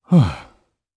Kibera-Vox_Sigh_jp.wav